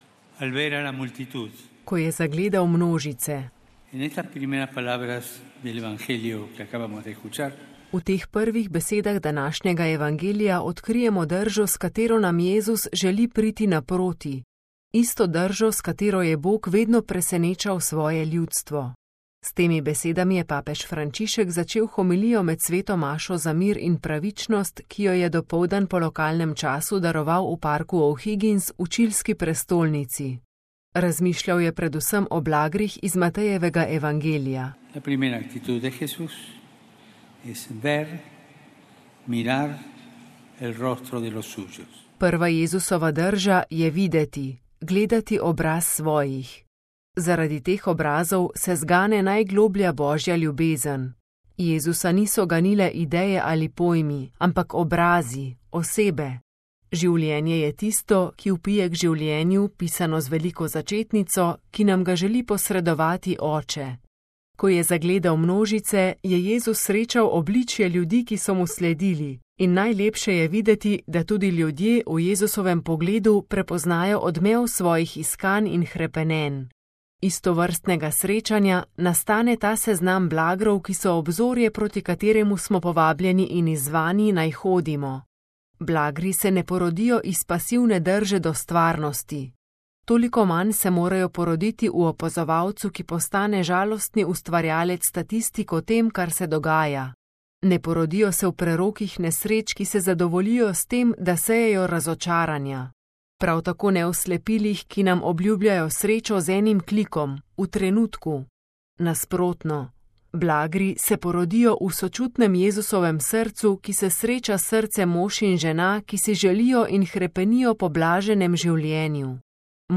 S temi besedami je papež Frančišek začel homilijo med sveto mašo za mir in pravičnost, ki jo je dopoldan po lokalnem času daroval v parku O'Higgins v čilski prestolnici. Razmišljal je predvsem o blagrih iz Matejevega evangelija.